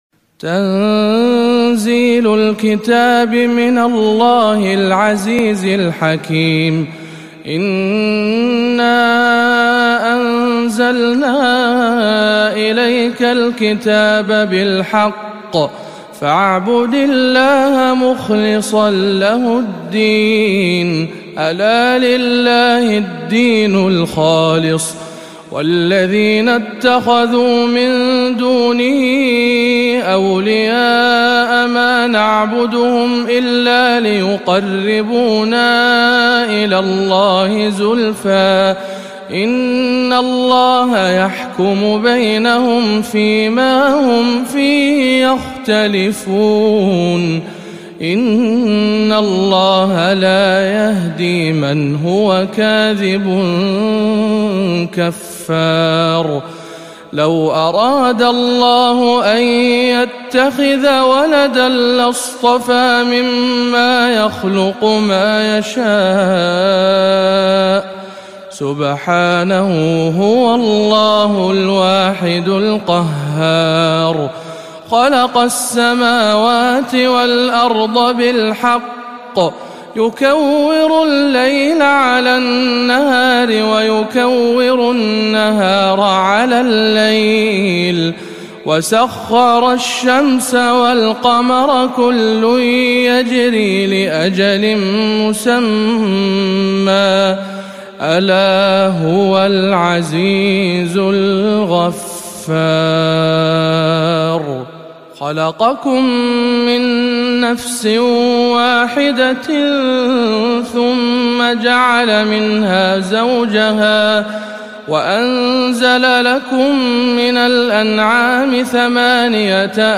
سورة الزمر بجامع الهدى بالشارقة بالإمارات العربية المتحدة